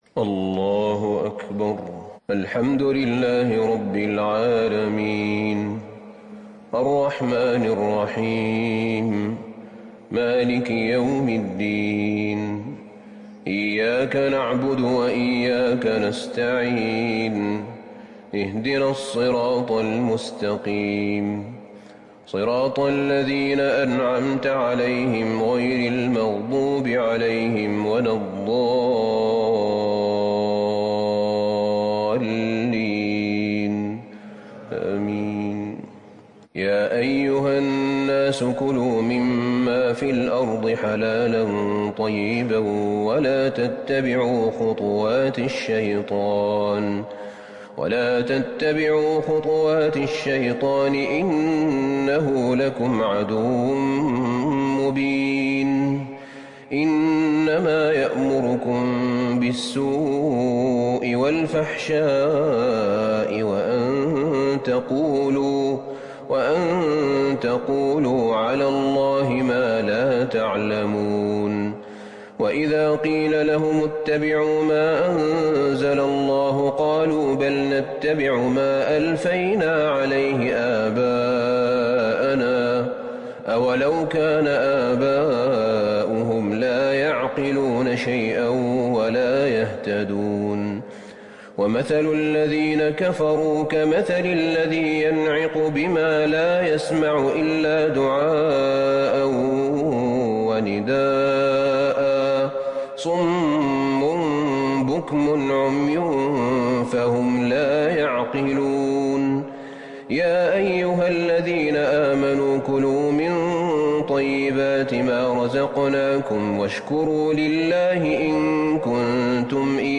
تهجد ٣٠ رمضان ١٤٤١هـ من سورة البقرة ١٦٨-٢١٨ > تراويح الحرم النبوي عام 1441 🕌 > التراويح - تلاوات الحرمين